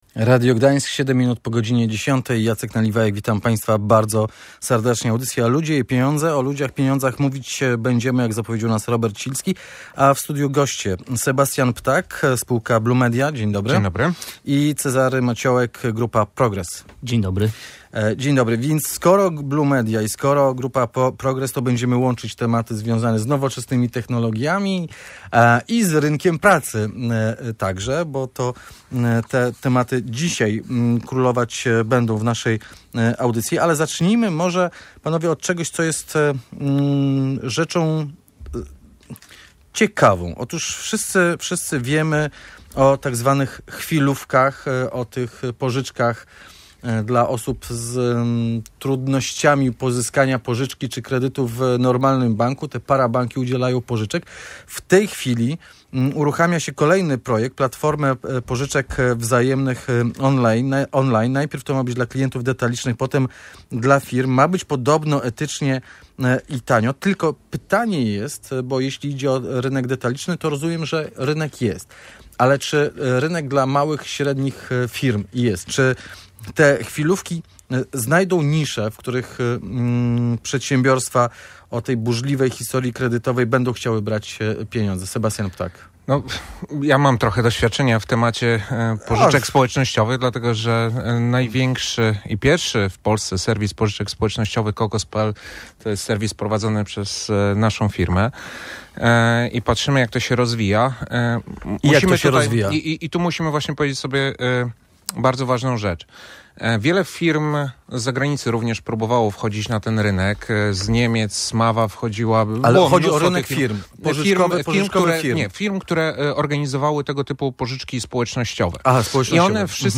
Nad tym, dlaczego polska edukacja nie spełnia wymagań studentów, zastanawiali się eksperci w audycji Ludzie i Pieniądze.